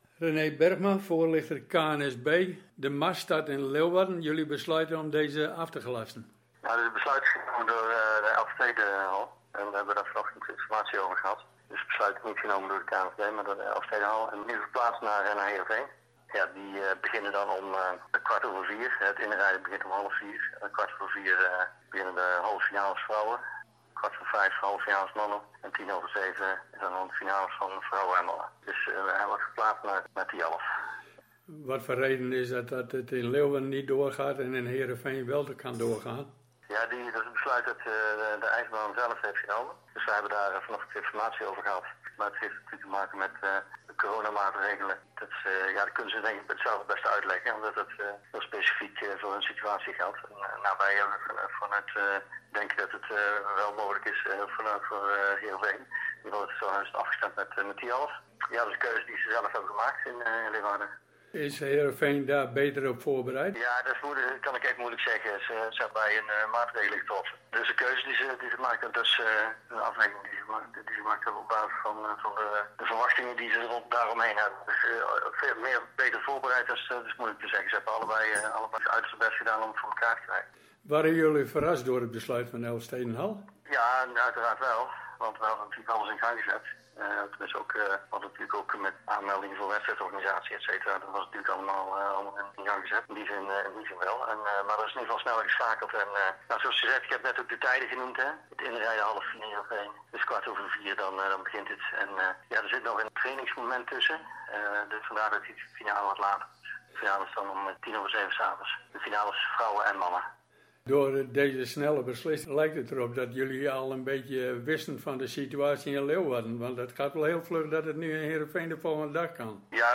Gesprek